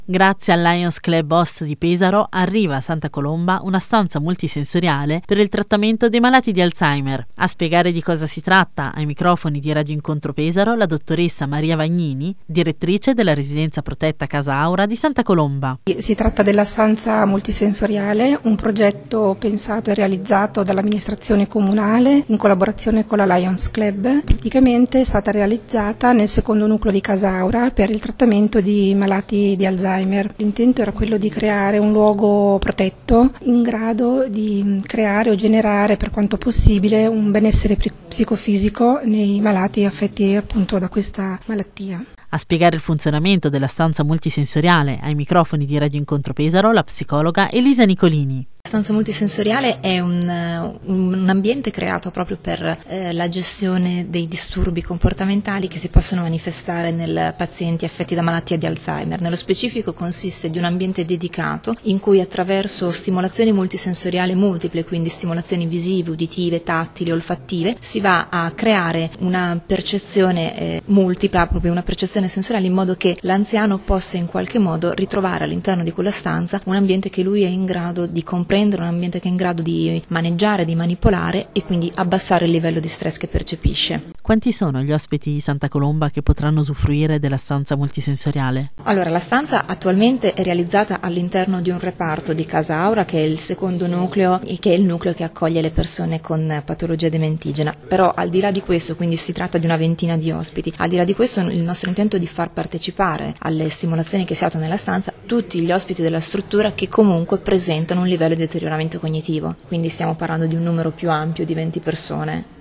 10/04/2013   Conferenza a Santa Colomba Interviste